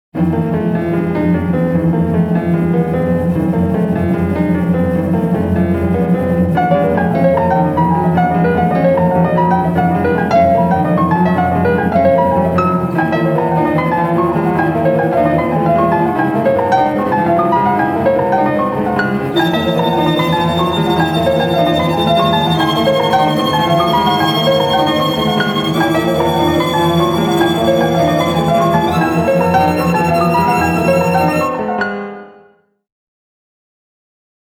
Horror.